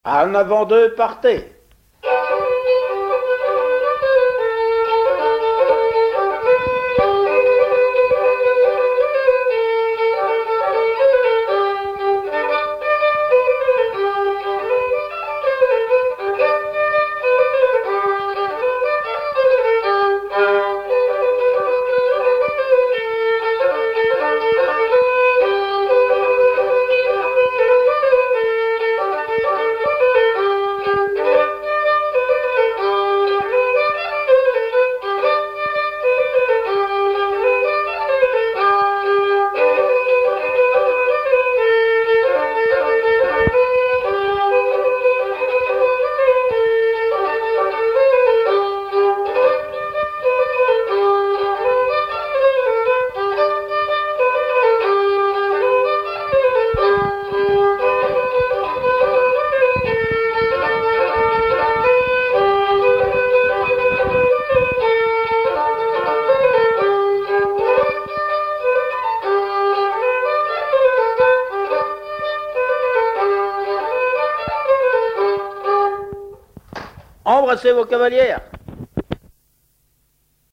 Divertissements d'adultes - Couplets à danser
branle : avant-deux
Pièce musicale inédite